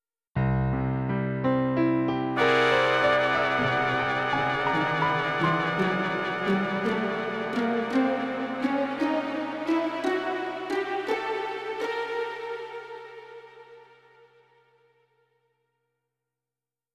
Натуральный звукоряд, целотонный аккорд, гамма тон-полутон.
А теперь все вместе: